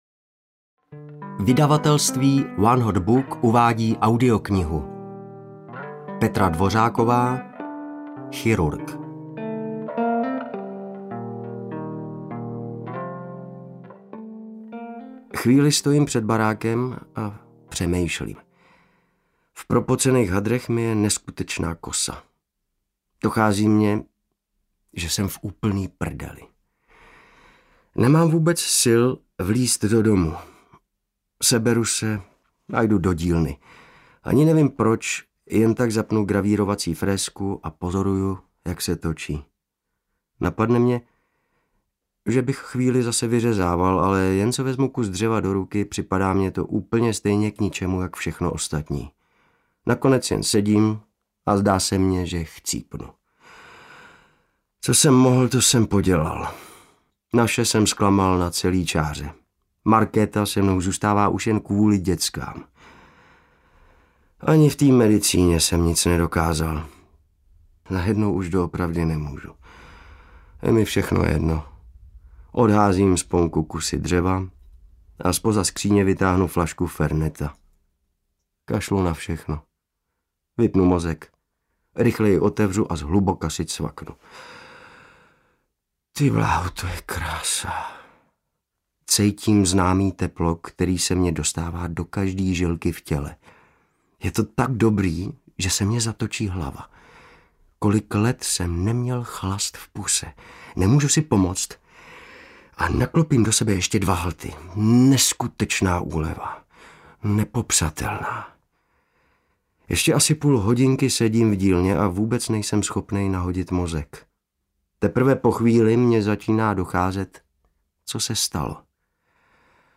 Chirurg audiokniha
Ukázka z knihy